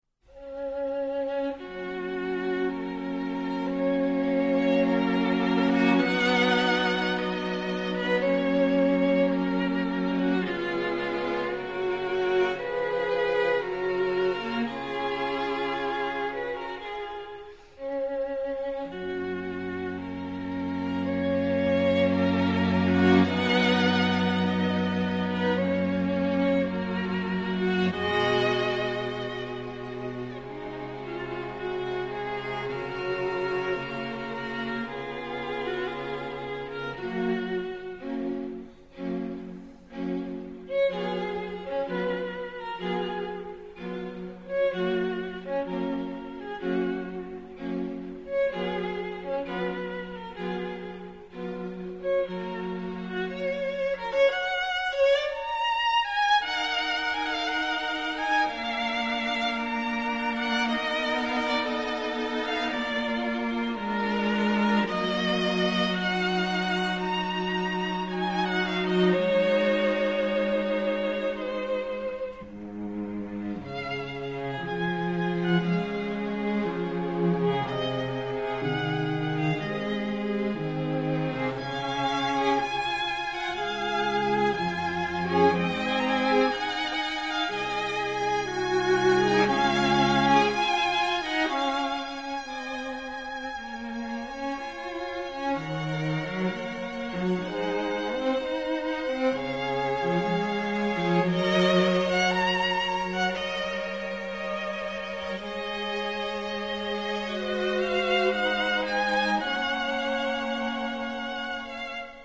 2 minutes of the second movement of Joseph Haydn's string quartet Op.76 #5: click here
quatuor.mp3